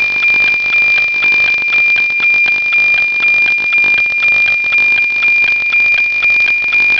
помехи от уличного освещения2